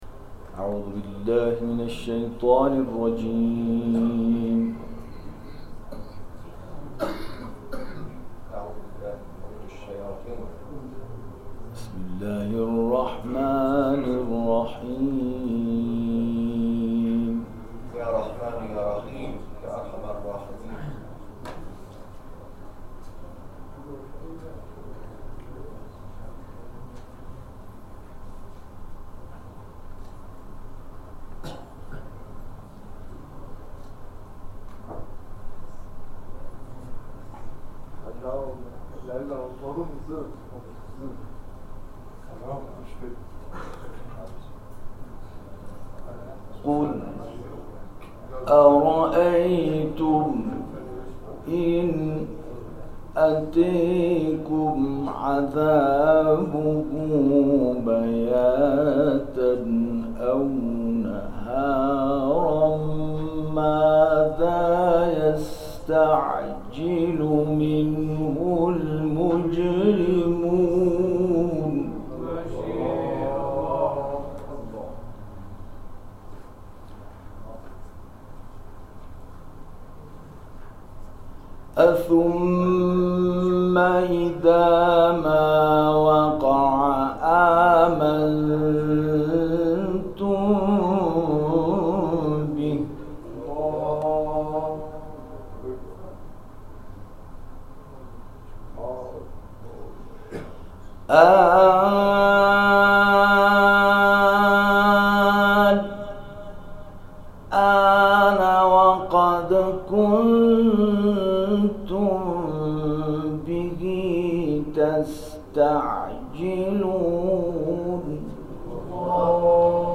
در ادامه تلاوت‌های منتخب این مراسم ارائه می‌شود.
تلاوت قرآن